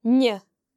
The slender N sound is made by pressing the tongue against the palate, and is made when the N occurs next to e or i in a word.